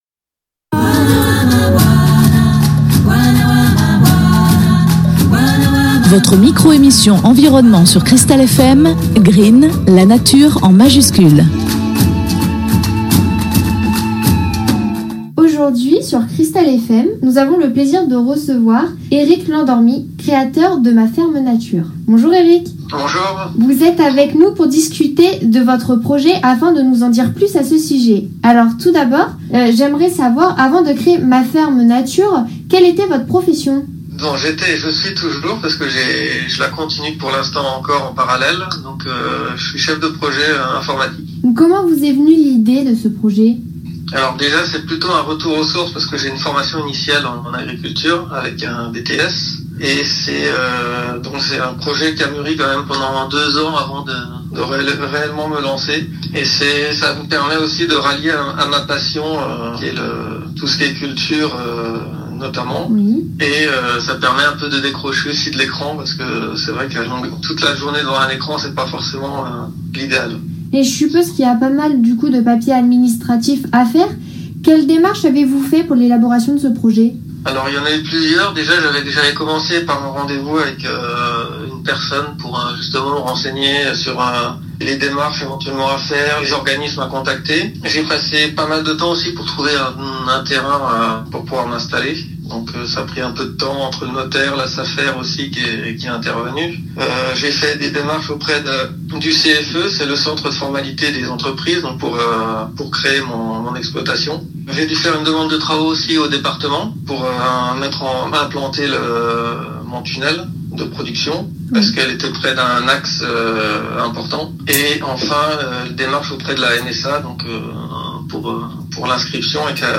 Une interview a été enregistrée en distanciel par téléphone du fait du contexte sanitaire actuel.